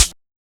Ghetto Cl Hat.wav